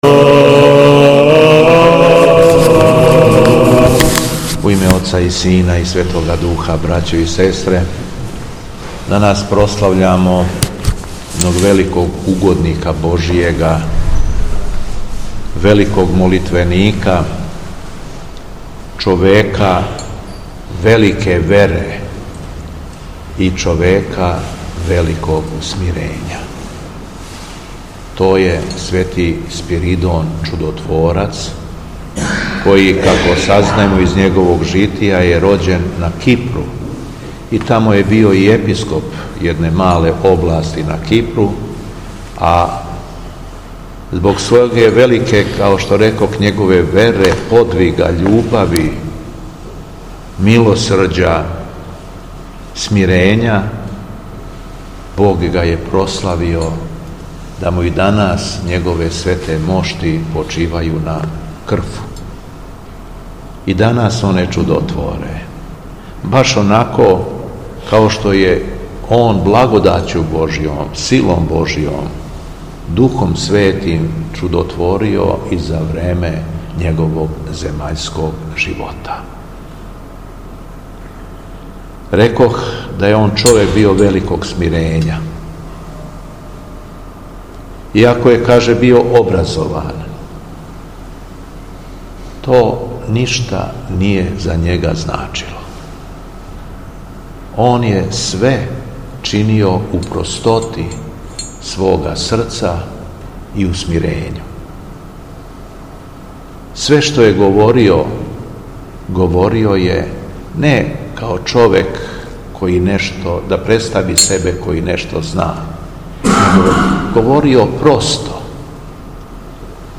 Беседа Његовог Високопреосвештенства Митрополита шумадијског г. Јована
Након прочитаног јеванђеља Митропилит Јован се обратио народу: